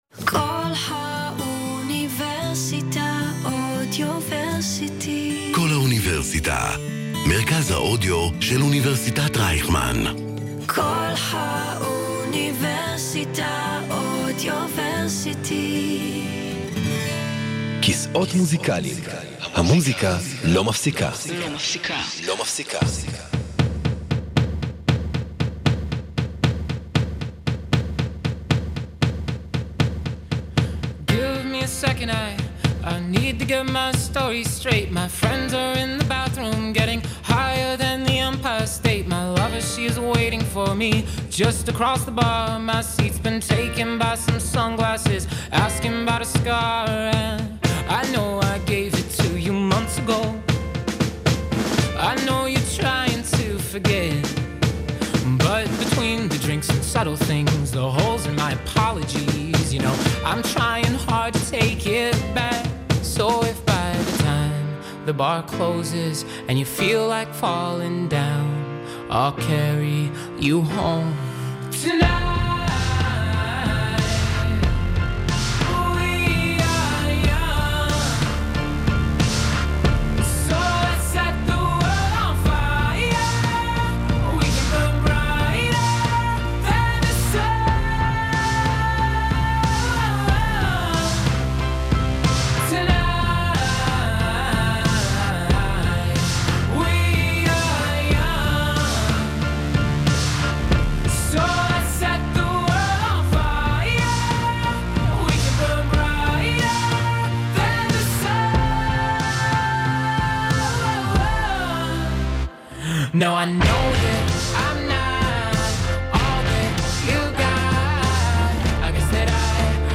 בכל שבוע הם מארחים באולפן איש מקצוע מוביל בתחומו בספורט בישראל: פסיכולוגים, רופאים, סקאוטים, תזונאים, ספורטאים ועוד.